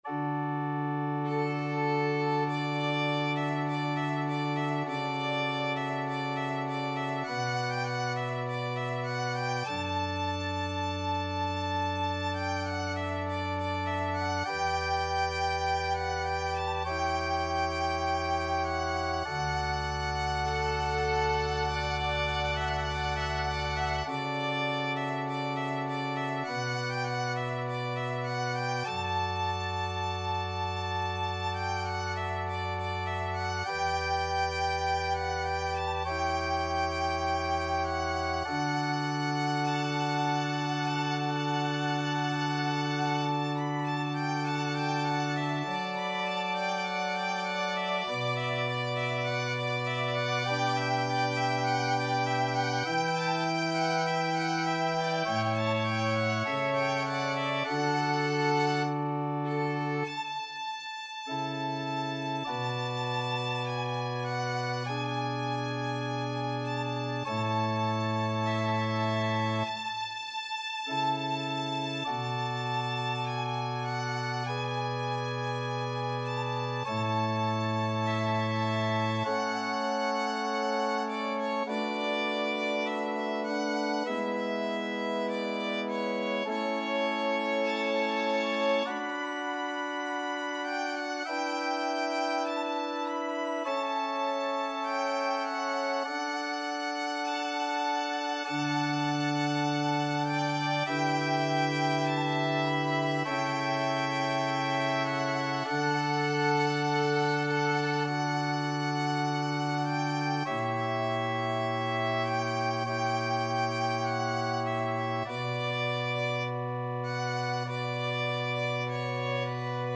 Violin 1Violin 2
4/4 (View more 4/4 Music)
Largo
Classical (View more Classical Violin Duet Music)